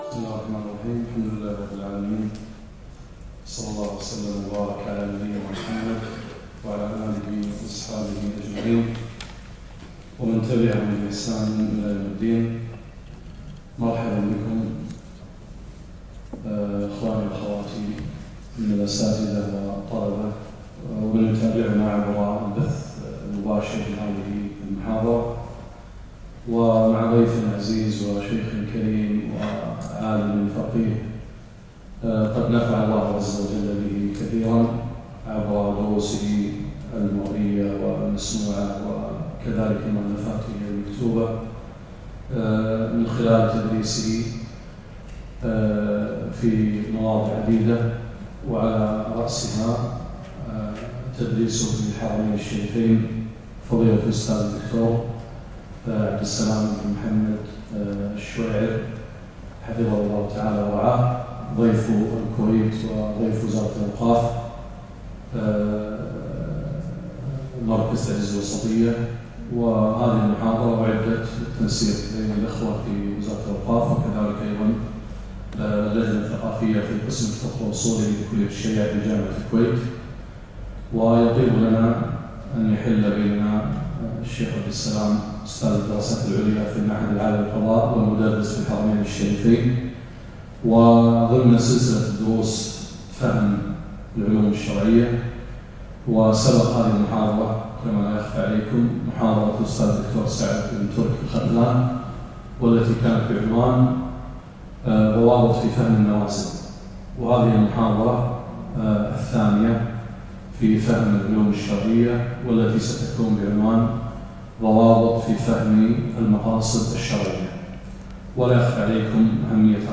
محاضرة - ضوابط فهم مقاصد الشريعة